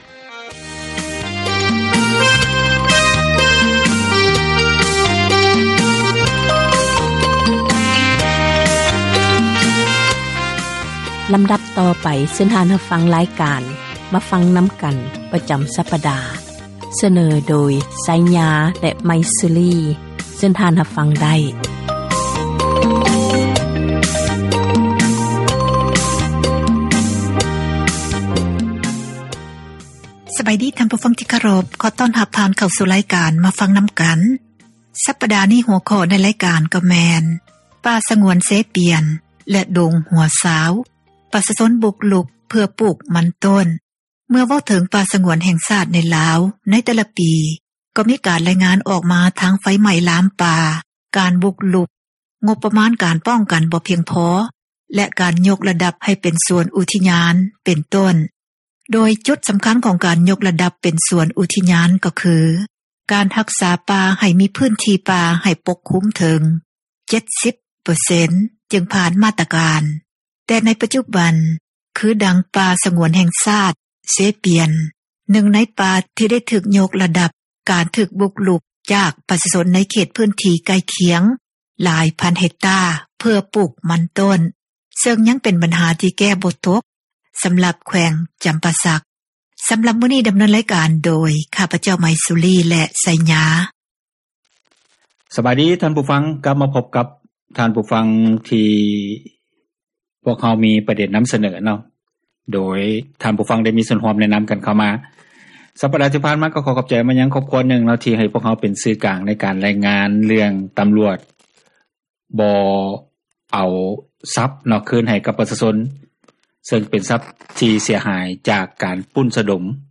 ການສົນທະນາ ໃນບັນຫາ ແລະ ຜົລກະທົບ ຕ່າງໆ ທີ່ເກີດຂຶ້ນ ຢູ່ ປະເທດລາວ